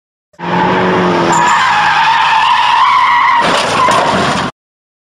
Car Skid To Crash Sound Effect Free Download
Car Skid To Crash